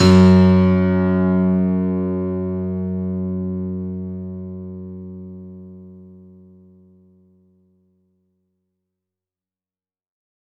F#1  DANCE-L.wav